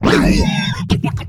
alianhit2.ogg